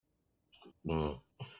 Play, download and share peidao original sound button!!!!
peidao.mp3